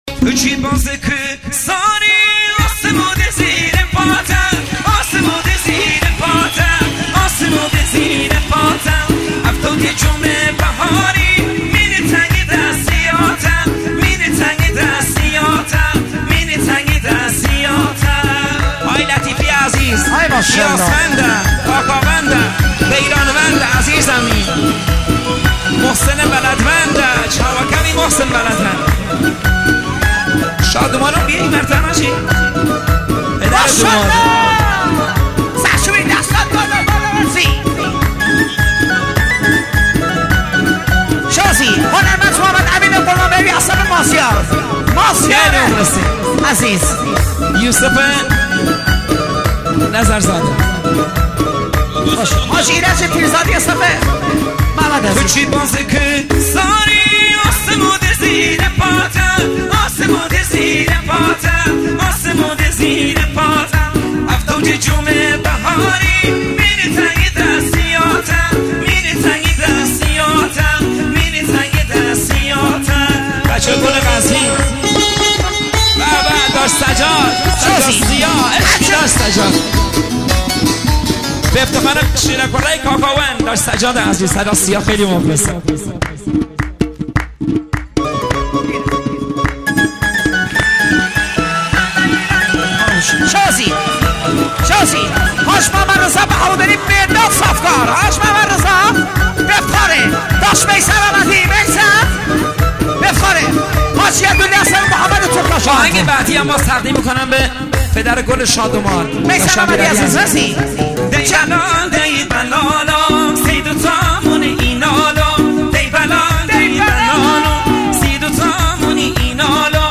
موسیقی شاد کردی